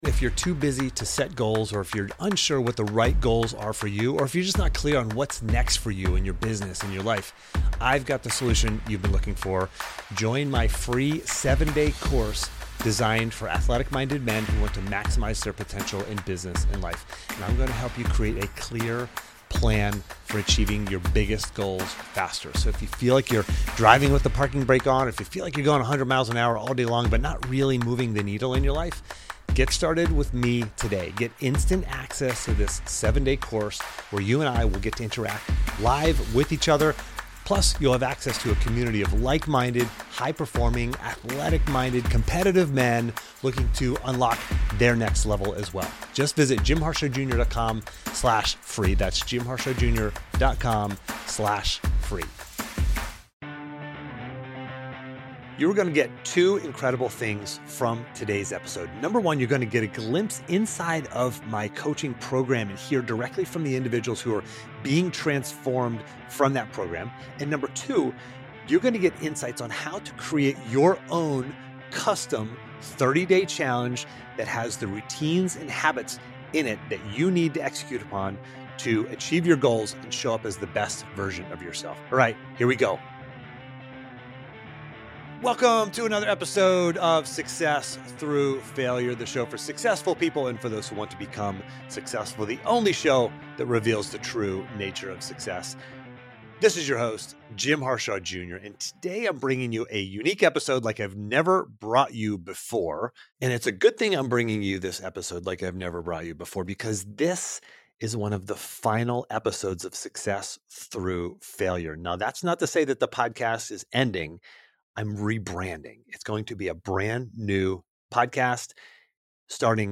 I interviewed the extraordinary individuals— all Pathfinders— who took on the challenge and finished by going 30-for-30.